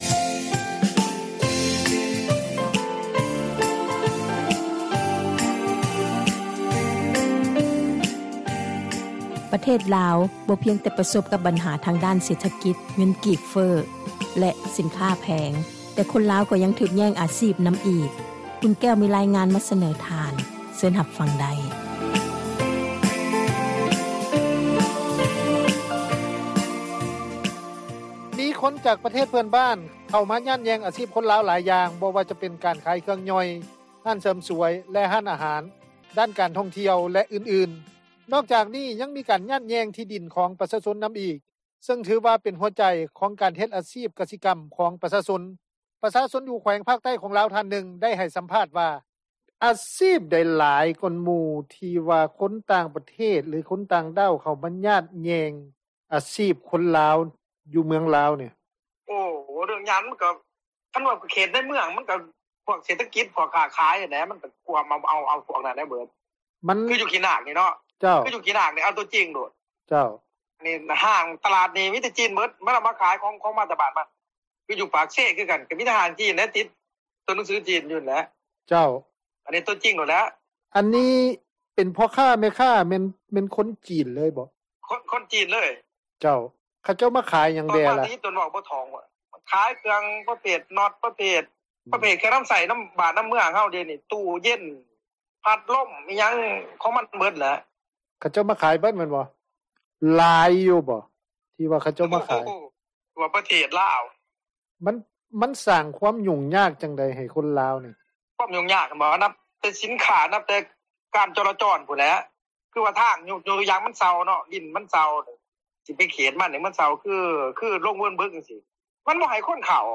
ປະຊາຊົນ ຢູ່ແຂວງພາກໃຕ້ຂອງລາວ ທ່ານນຶ່ງ ໄດ້ໃຫ້ສໍາພາດວ່າ:
ນັກທຸຣະກິຈ ທ່ານນຶ່ງ ໄດ້ໃຫ້ສໍາພາດ ຕໍ່ວິທຍຸ ເອເຊັຽ ເສຣີ ວ່າ: